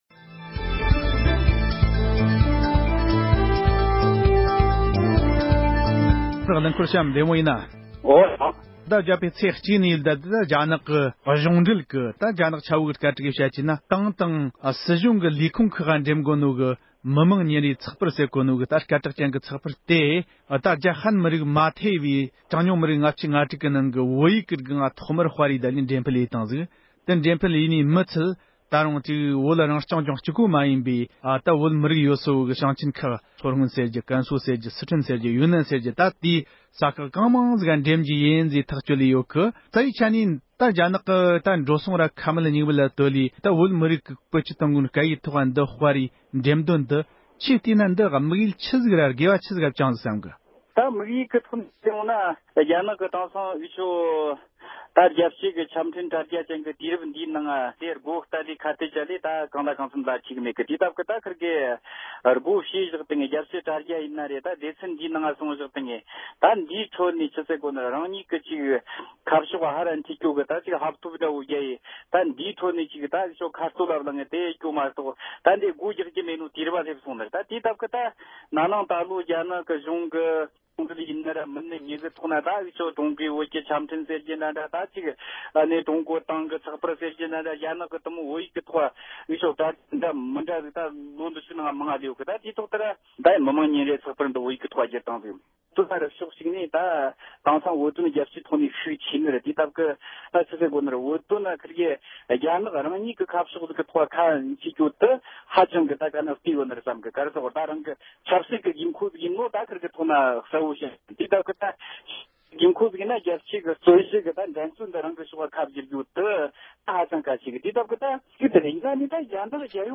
སྒྲ་ལྡན་གསར་འགྱུར།
གླེང་མོལ་ཞུས་པ་ཞིག